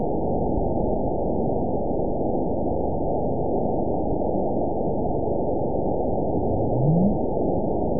event 917084 date 03/19/23 time 00:12:32 GMT (2 years, 1 month ago) score 9.68 location TSS-AB01 detected by nrw target species NRW annotations +NRW Spectrogram: Frequency (kHz) vs. Time (s) audio not available .wav